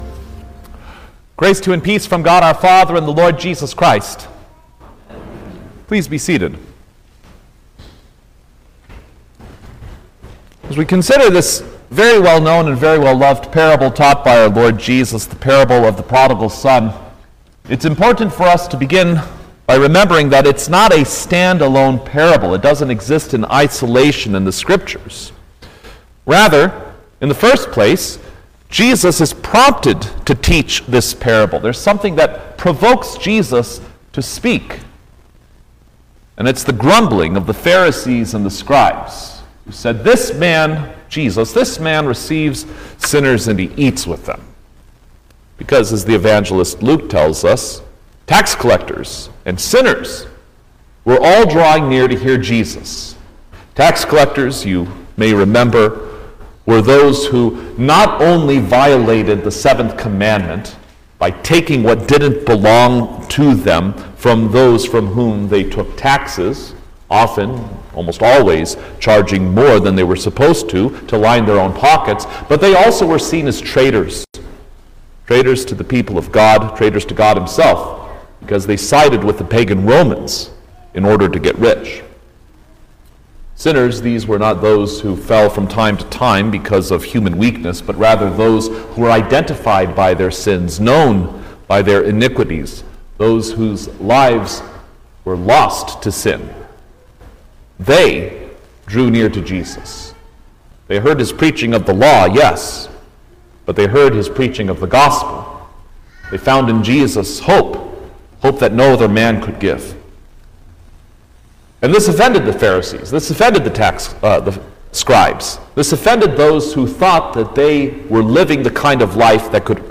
July-6_2025_Third-Sunday-after-Trinity_Sermn-Stereo.mp3